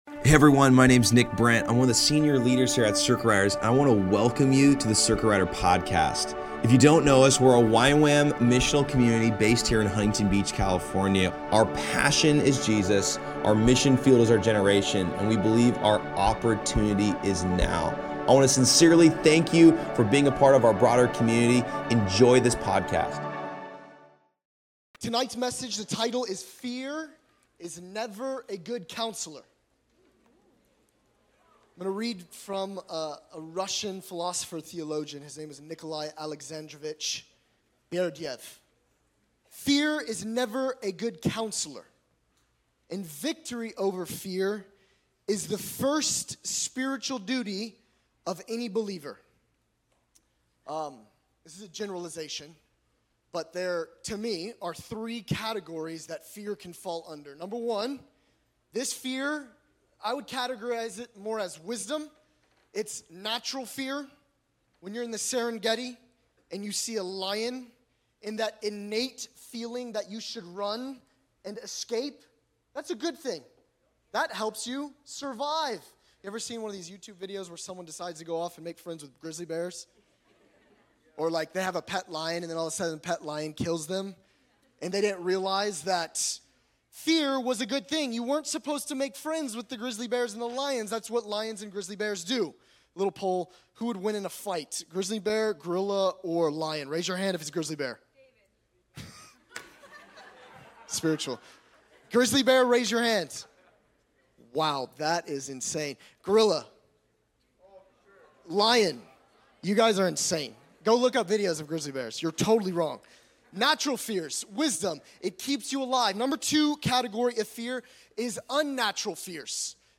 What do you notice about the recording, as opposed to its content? At Circuit Riders Monday Nights in Costa Mesa CA